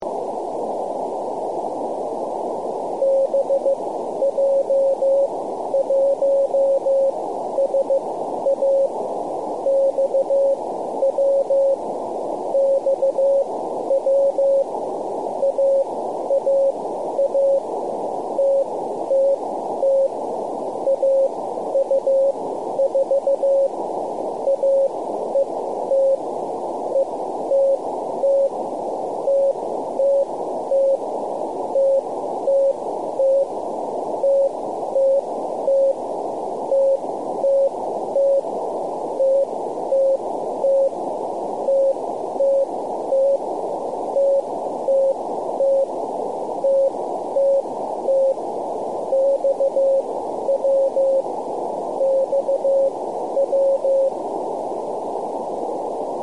Записи сигналов спутников
Модуляция: CW